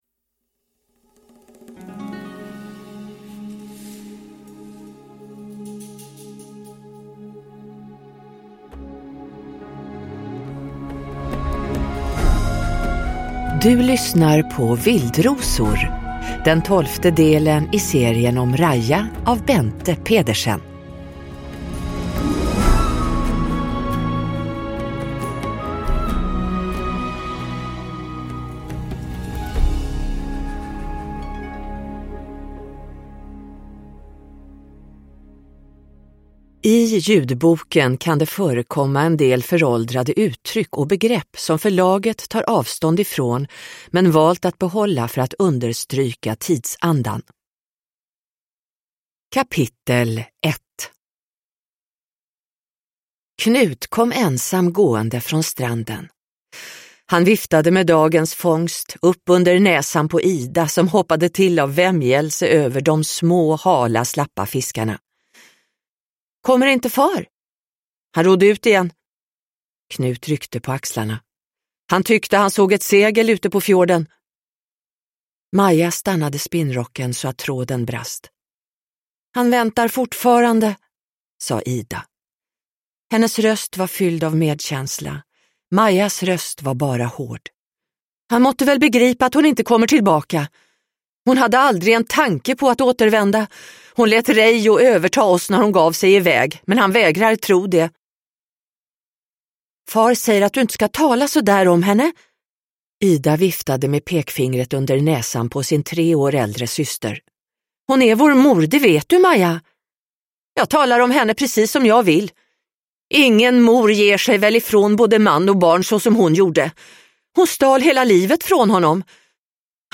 Vildrosor – Ljudbok